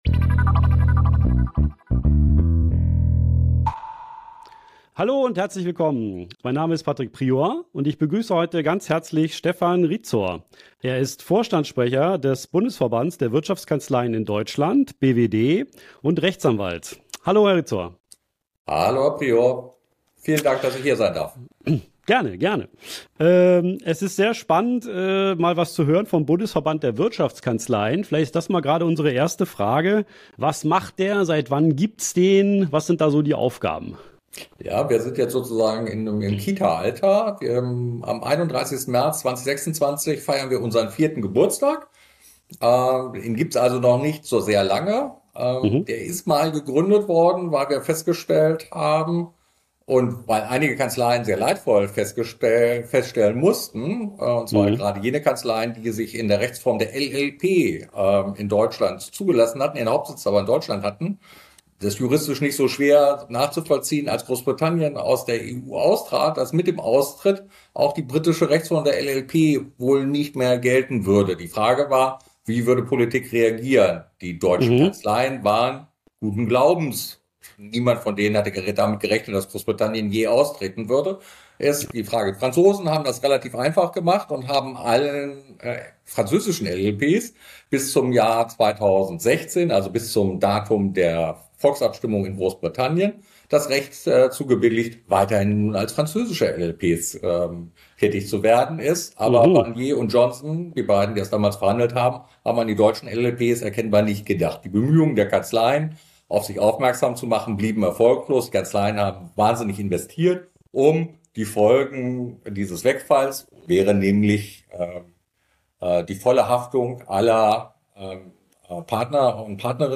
In der aktuellen Folge des Legal Tech Verzeichnis Podcast interviewt